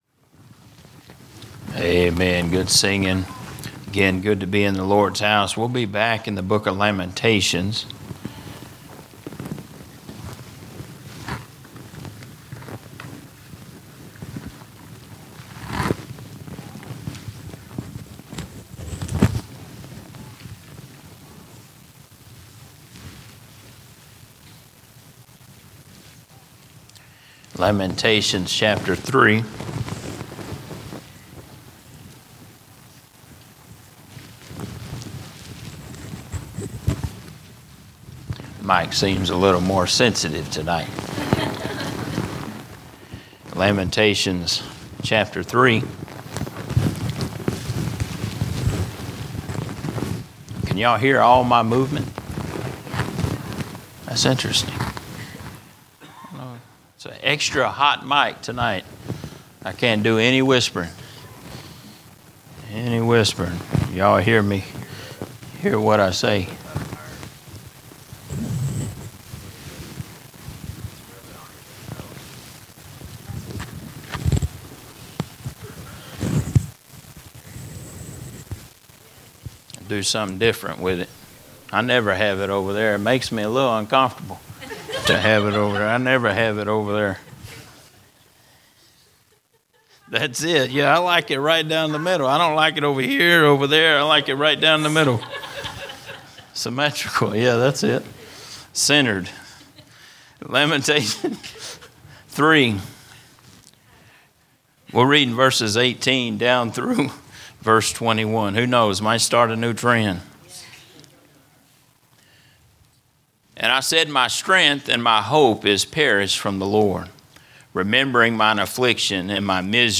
Sermons not part of a specific series